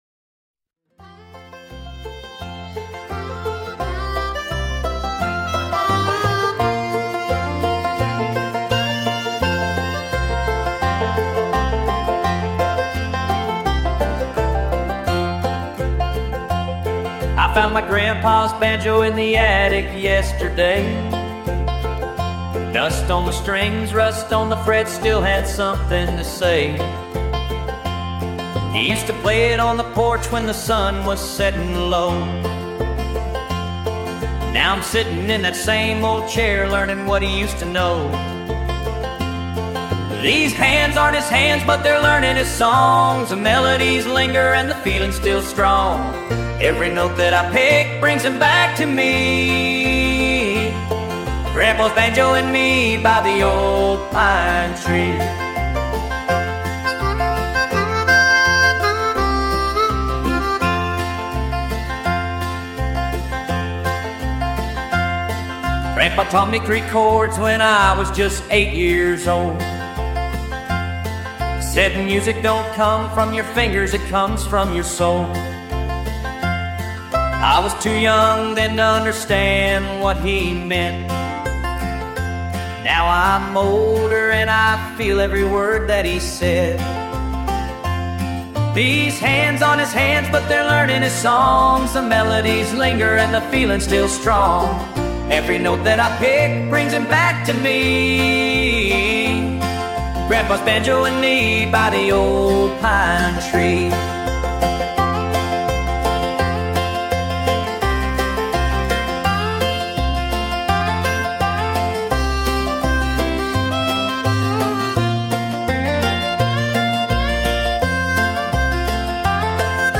8-beat intro.
Play parts: Verse, Chorus, Verse, Chorus, Bridge, Verse, Chorus.